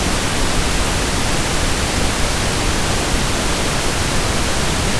Remember that pink noise has an equal amount of energy within each octave, while speech contains most of its energy below 5 kHz.